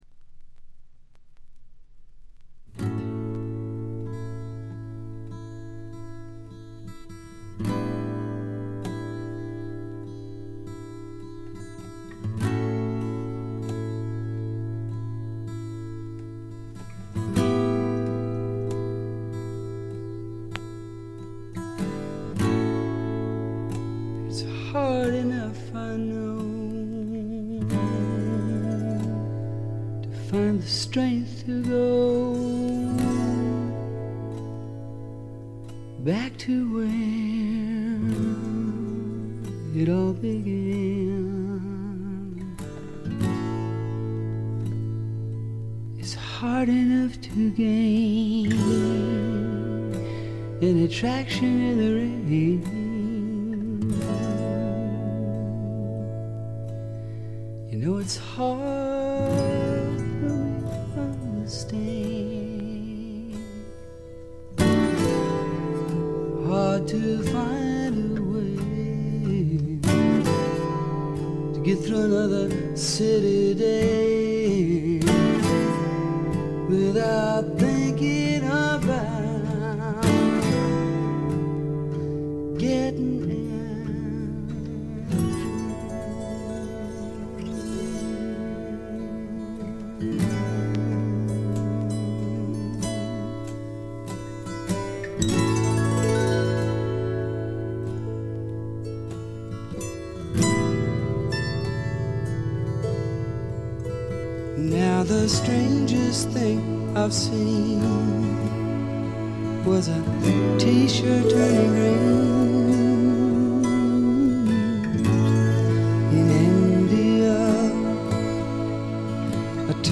B2イントロでプツ音1回。
試聴曲は現品からの取り込み音源です。
Autoharp